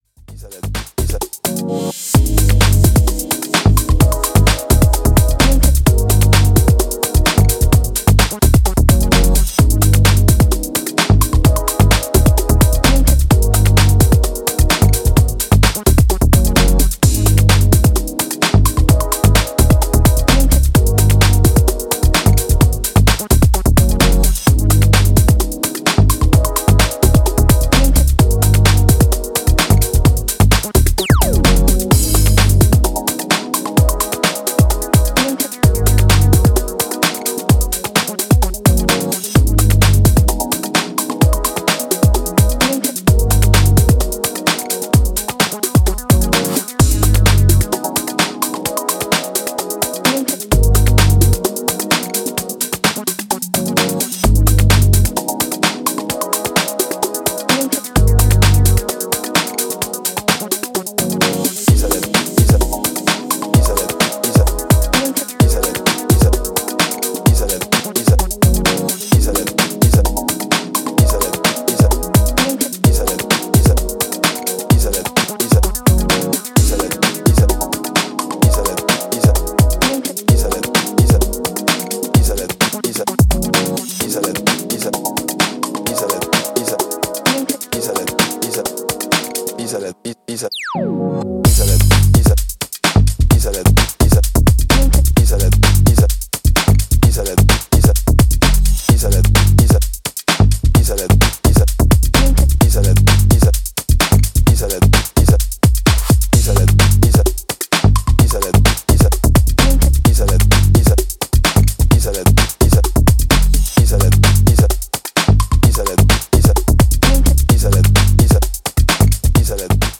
親しみやすいメロディーの浮沈で淡々と横揺れを誘うブレイクビーツ・ハウス